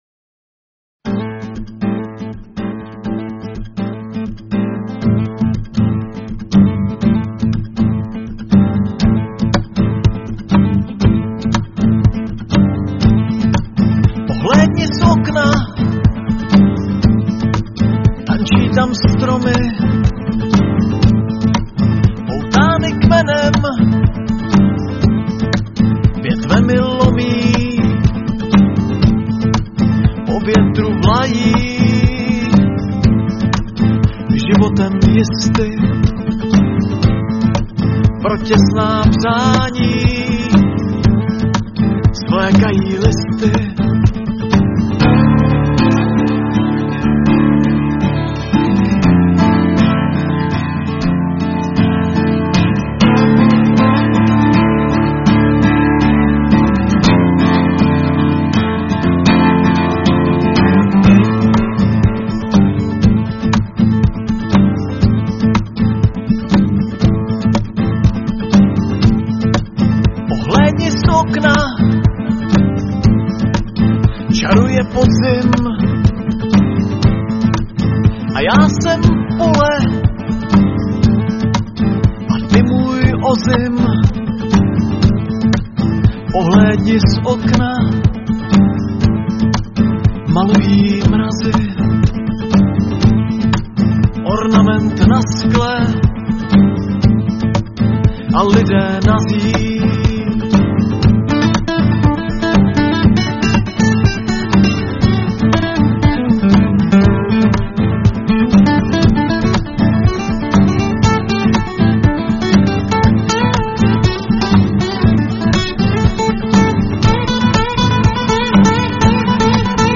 Anotace: Tentokrát omluvte horší kvalitu zvuku, musela jsem soubor hodně zmenšit.
Žádné AI. Pravá hudba, pravý hlas a můj text.